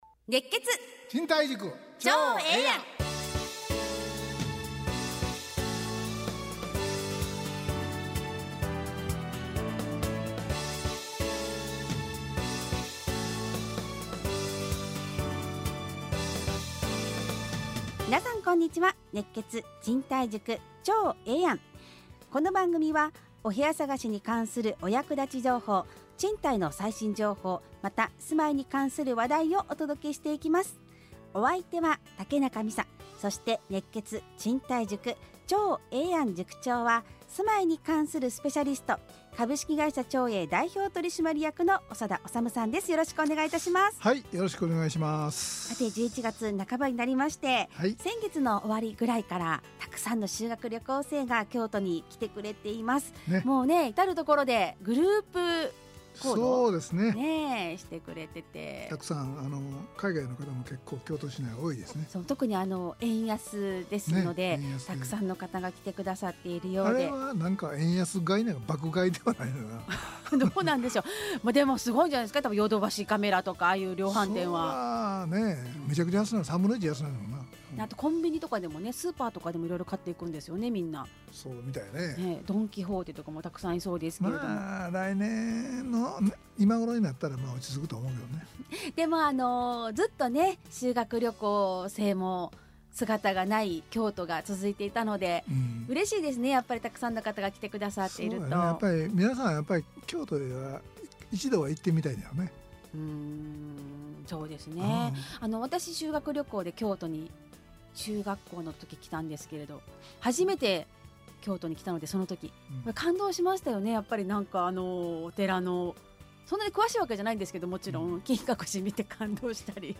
ラジオ放送 2022-11-21 熱血！